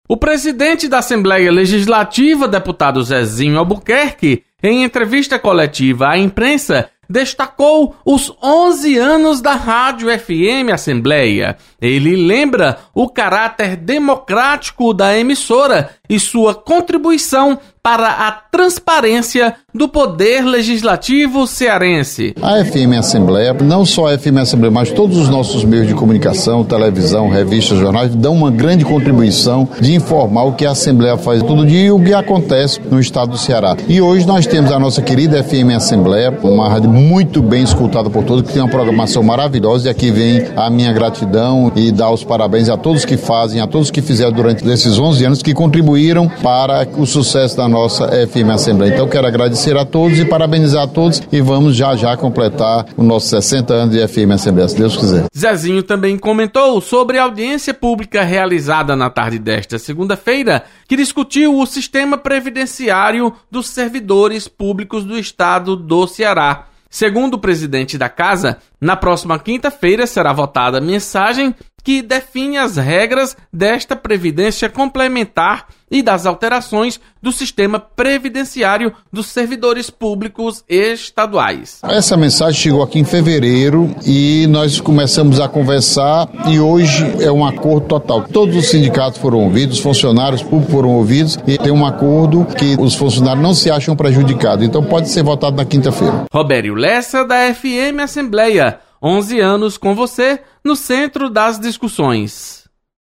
Deputado Zezinho Albuquerque destaca 11 anos da FM Assembleia. Repórter